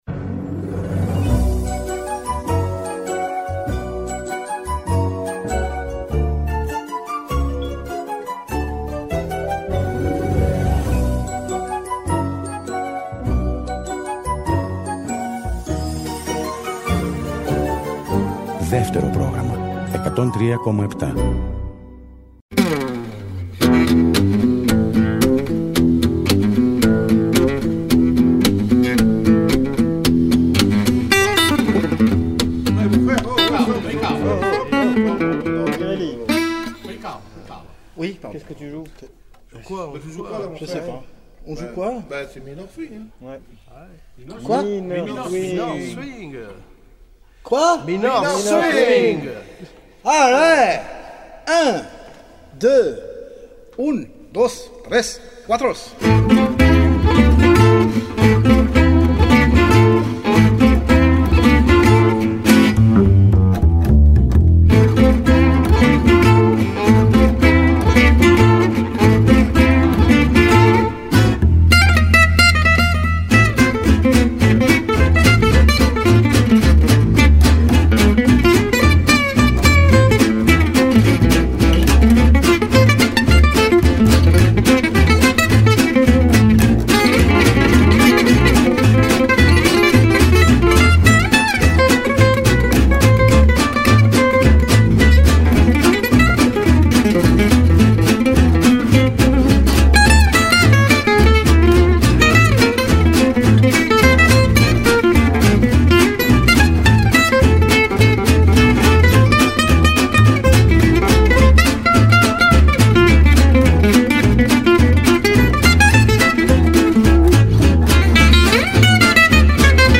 Παρασκευή βράδυ και τα τραγούδια σε tempo allegro διηγούνται Ιστορίες καθημερινότητας.